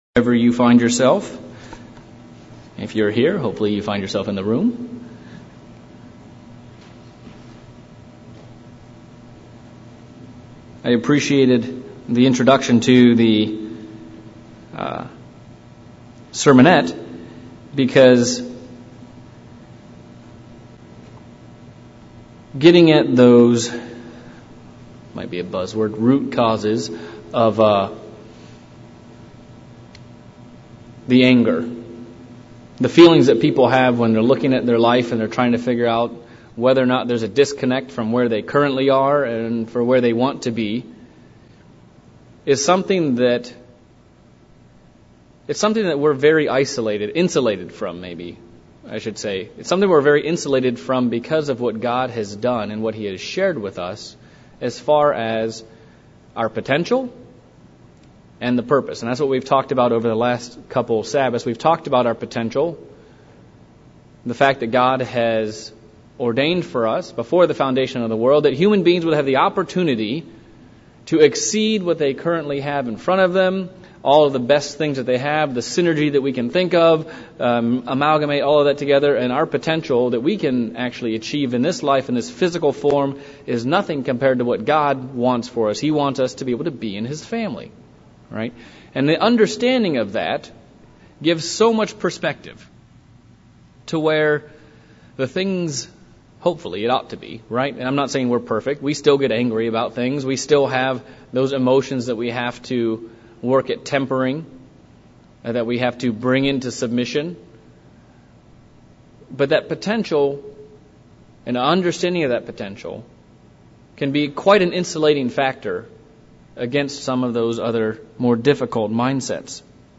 Part 2 of a 3 part sermon series. What is our purpose in life and did God just put us on this planet for no particular reason?